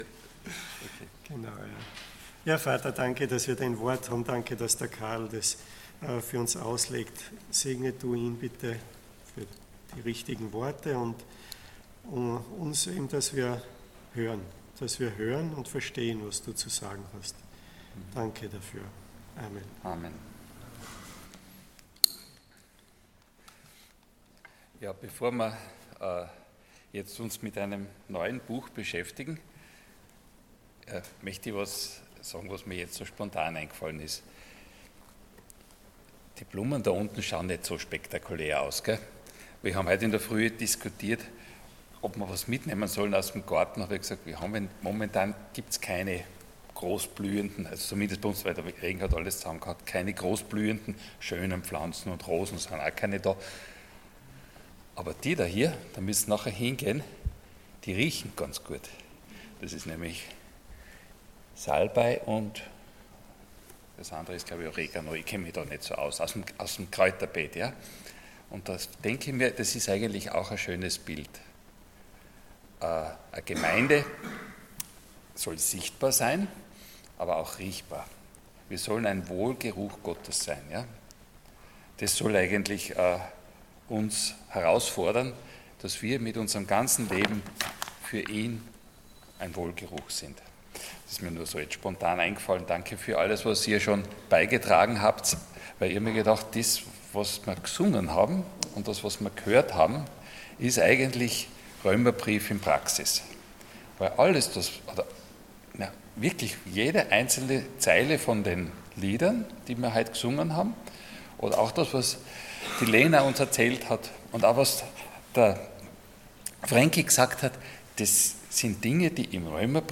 Prediger
Passage: Romans 1:1-5 Dienstart: Sonntag Morgen